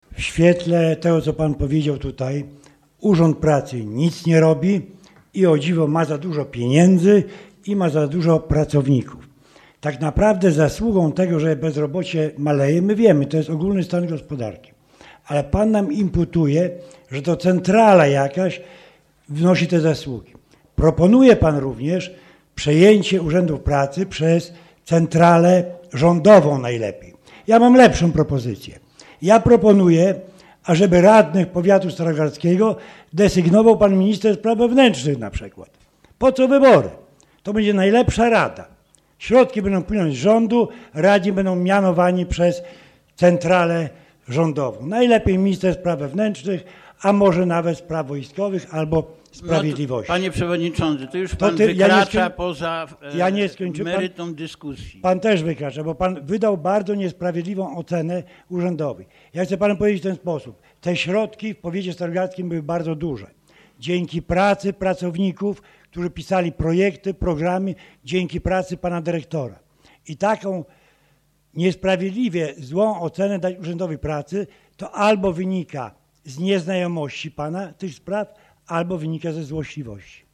Co więcej podczas ostatniej sesji Rady Powiatu radny Prawa i Sprawiedliwości stwierdził, że w Powiatowym Urzędzie Pracy zauważa wyraźny przerost zatrudnienia.
Do dyskusji włączył się przewodniczący Rady Wiesław Brzoskowski, który w ironiczny sposób odniósł się do wypowiedzi radnego Głucha.